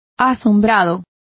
Complete with pronunciation of the translation of amazed.